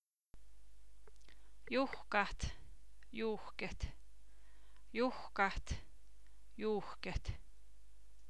Her kan du sammenlikne lang og kort u, selv om ordene skrives likt:   juhke